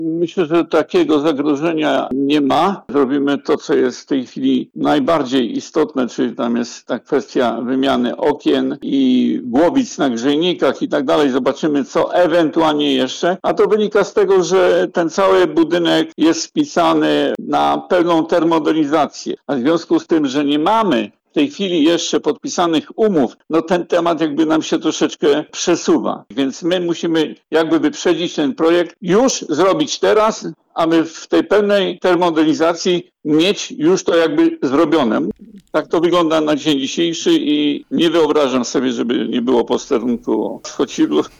Burmistrz uspokaja